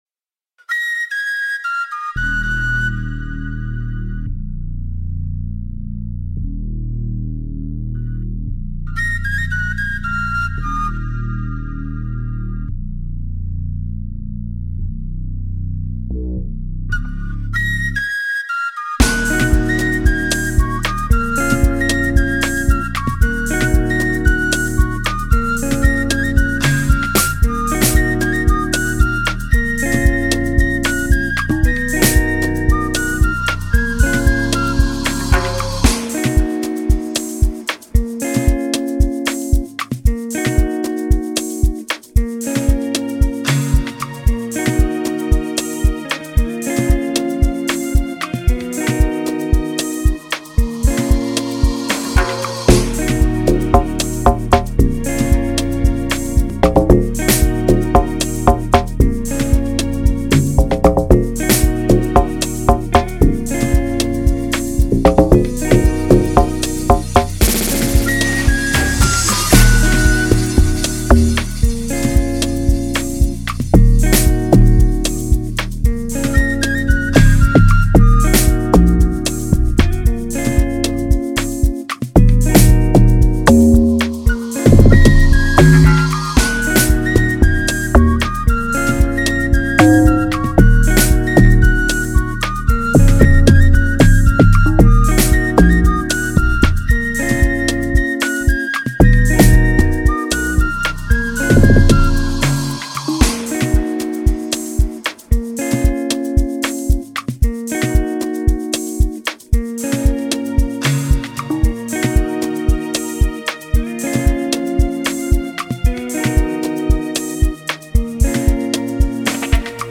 2024 in Dancehall/Afrobeats Instrumentals